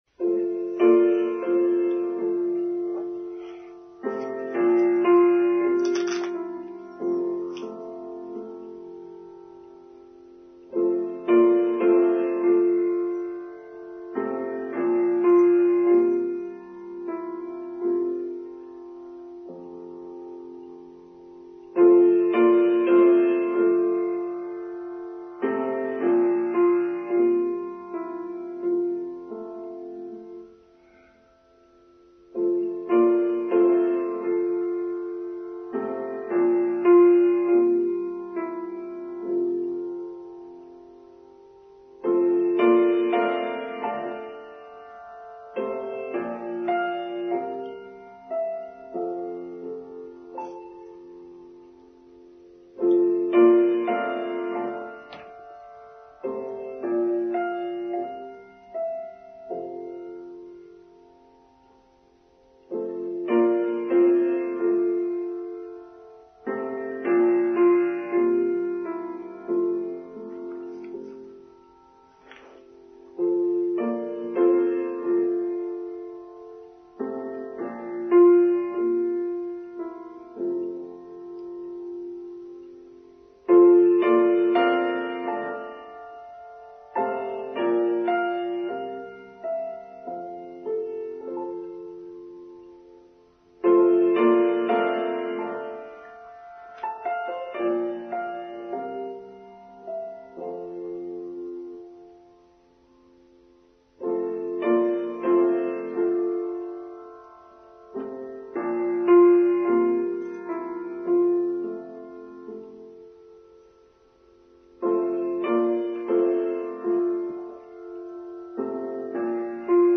John Bunyan: Online Service for Sunday 27th August 2023